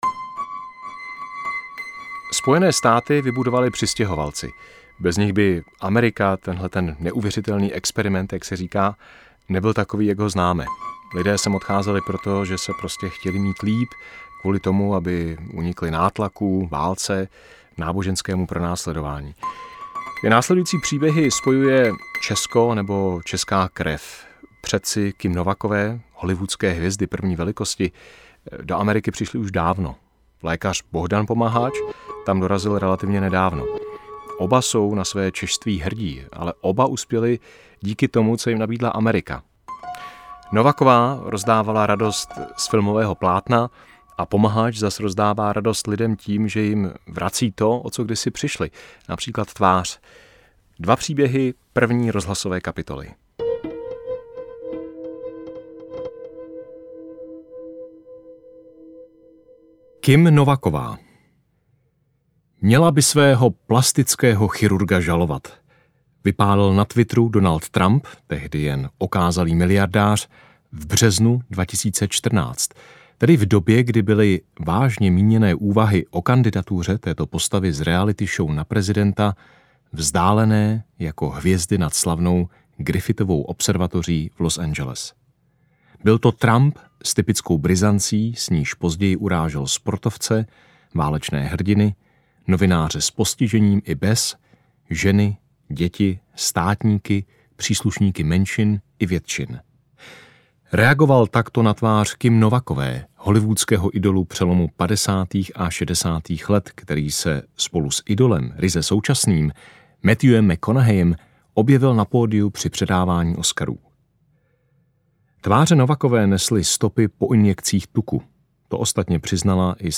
Interpret:  Martin Řezníček
Autorská četba z knihy moderátora Událostí České televize Martina Řezníčka o pěti letech, které strávil v USA jako zahraniční zpravodaj ČT.